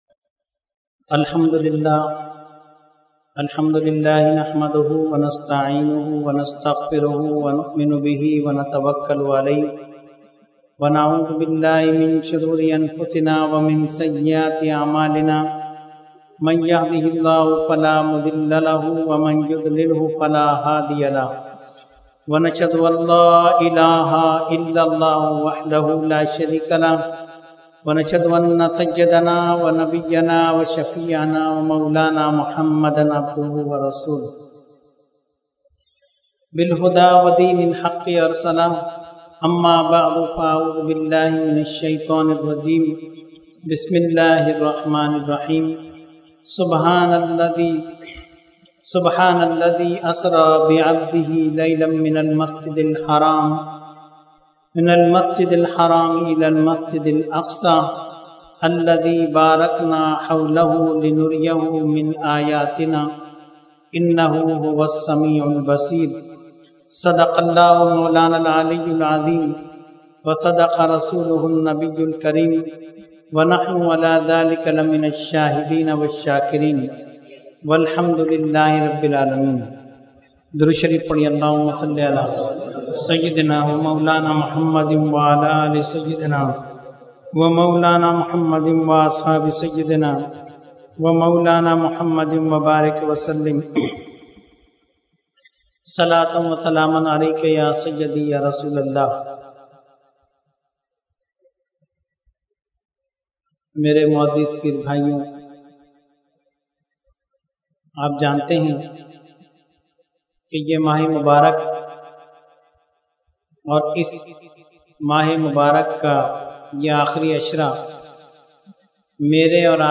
Weekly Tarbiyati Nashist held on 2/6/2013 at Dargah Alia Ashrafia Ashrafabad Firdous Colony Karachi.
Category : Speech | Language : UrduEvent : Weekly Tarbiyati Nashist